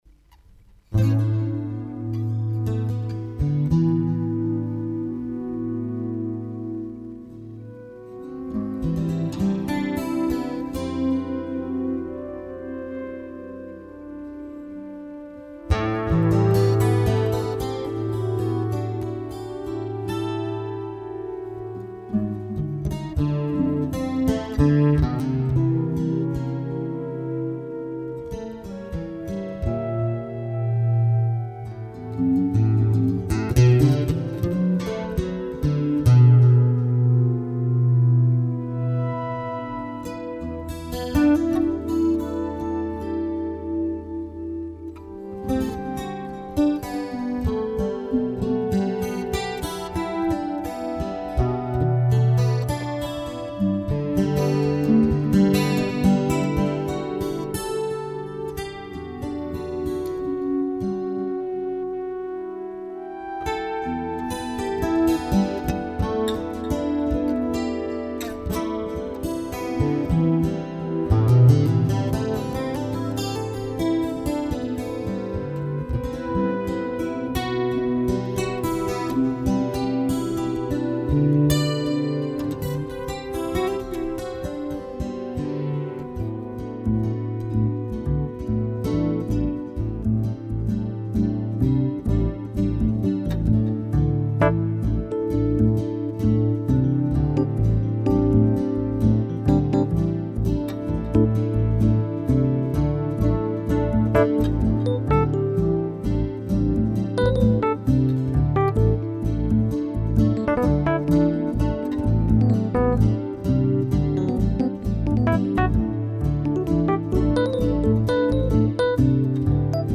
Mann ist eine freie Improvisation, ebenfalls als
Synthesizer
Gitarre